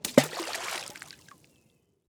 Plusk.ogg